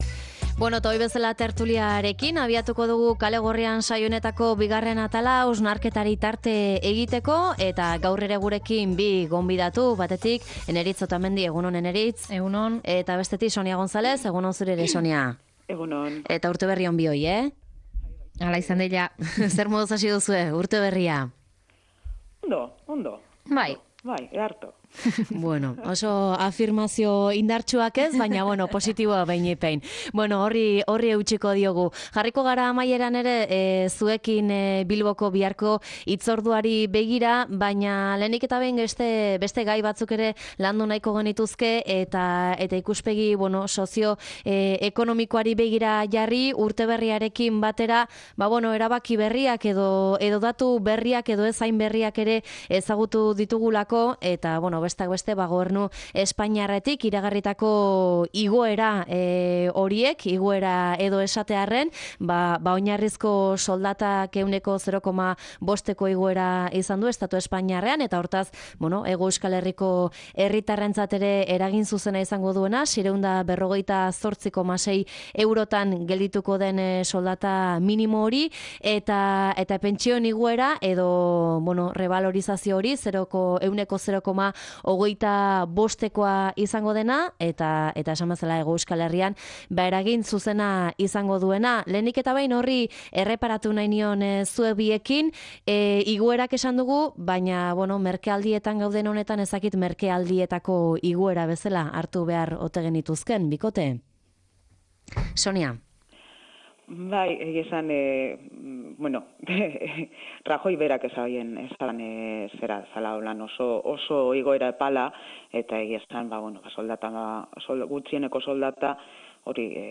Tertulia: merkealdietako lan baldintzak